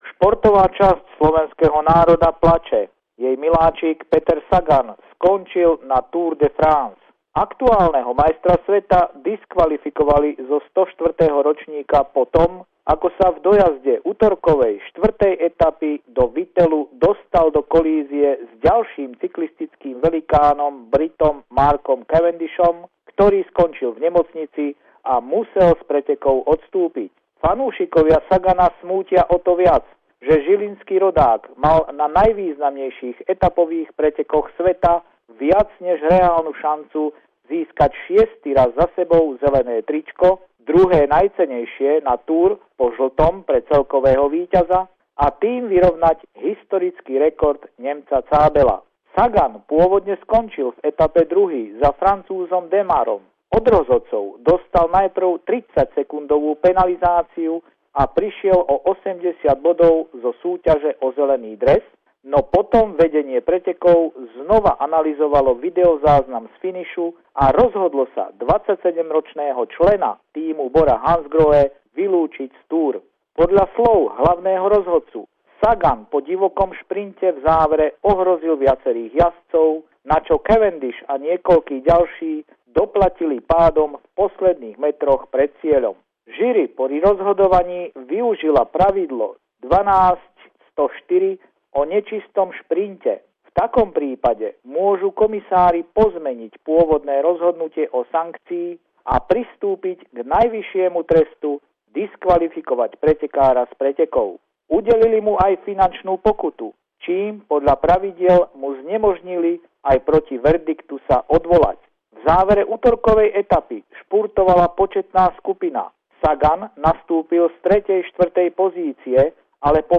Ohlasy na diskvalifikáciu Petra Sagana z Tour de France. Pravidelný telefonát týždňa